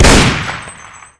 mp44_shoot.wav